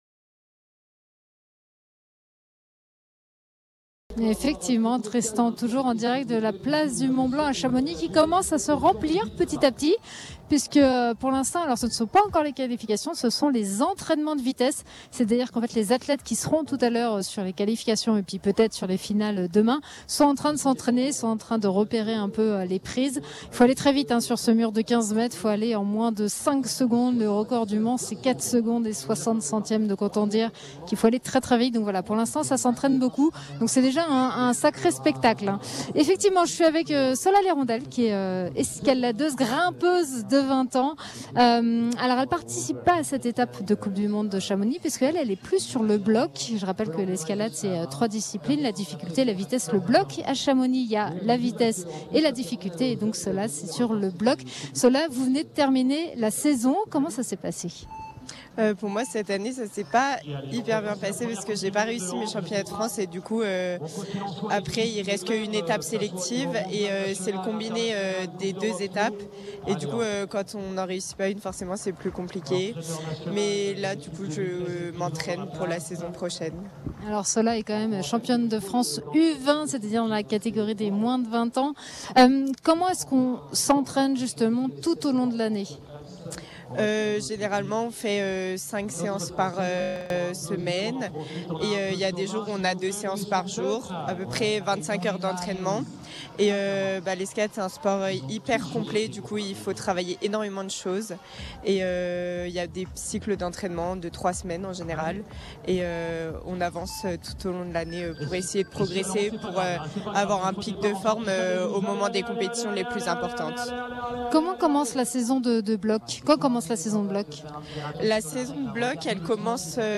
Radio Mont Blanc en direct de la Coupe du Monde d’Escalade à Chamonix !